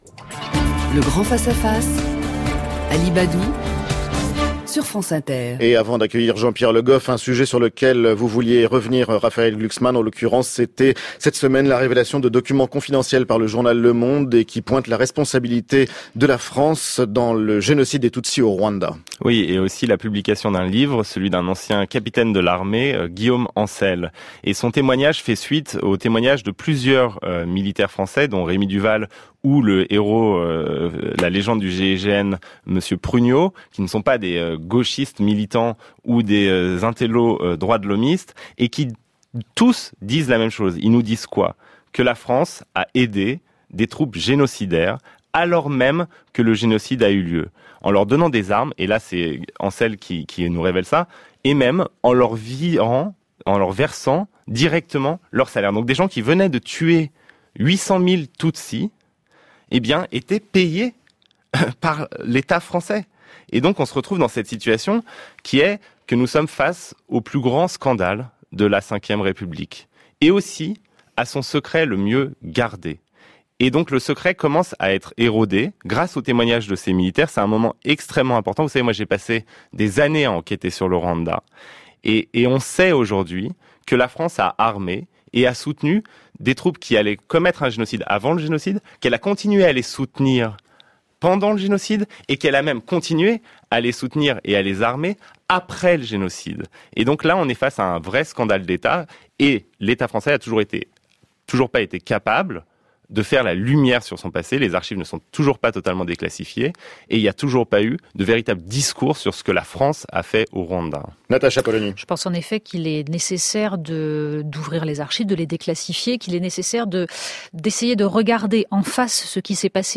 1. Le duel Natacha Polony, Raphaël Glucksmann
(extrait du « Grand Face-à-face » par Ali BADDOU sur France Inter – 18/3/2018) :